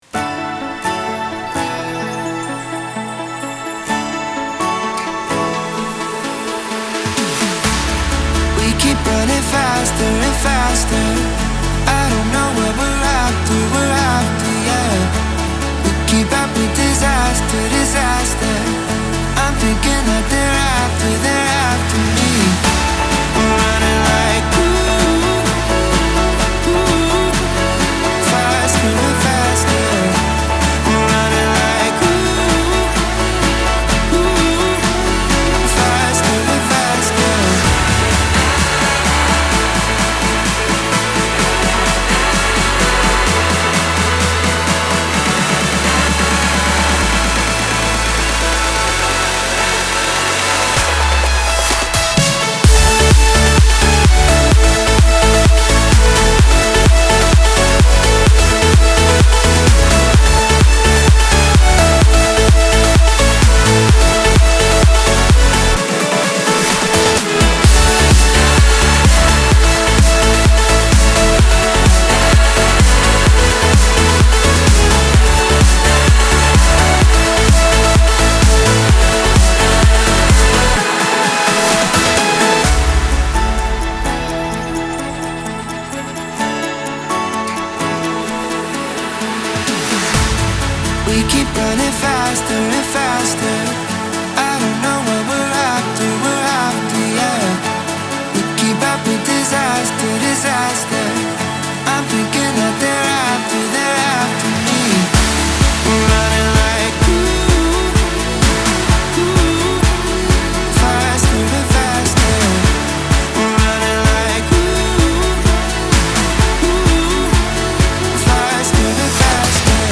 • Жанр: House